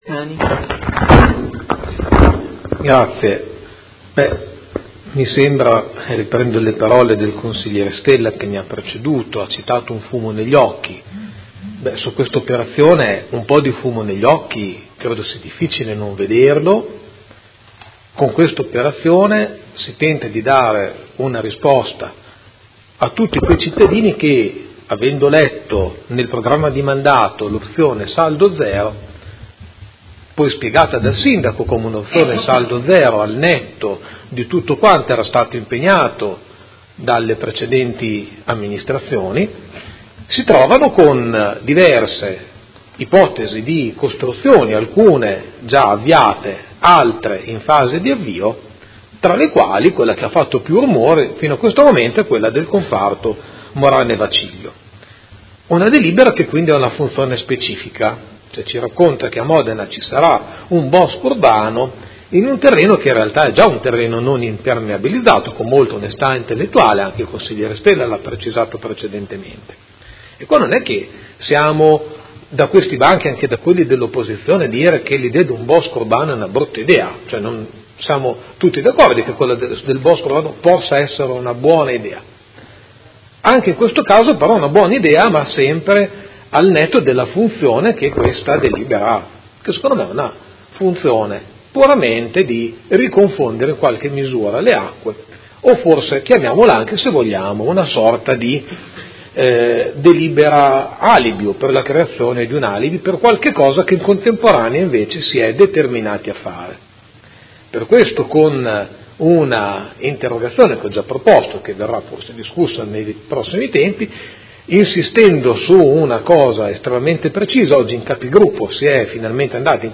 Giuseppe Pellacani — Sito Audio Consiglio Comunale
Seduta del 01/02/2018 Dibattito.